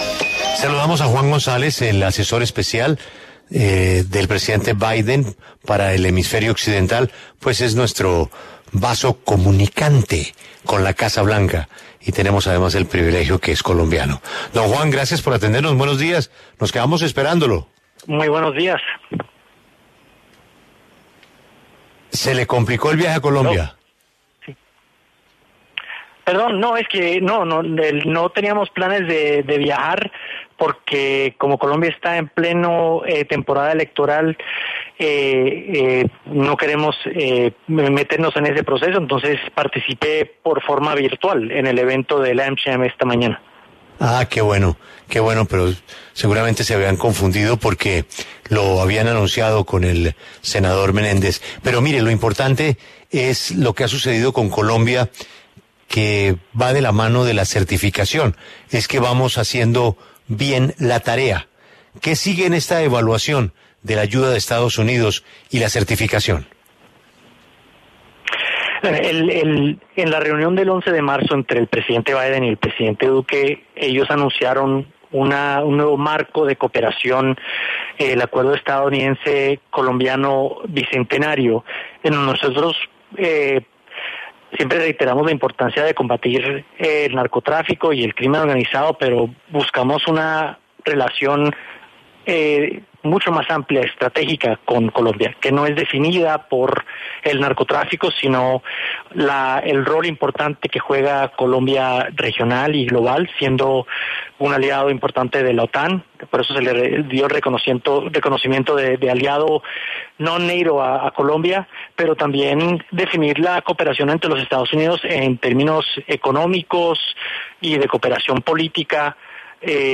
Juan González, asesor del presidente Joe Biden, habló en La W sobre la certificación de EE.UU. a Colombia en el desempeño en lucha antinarcóticos.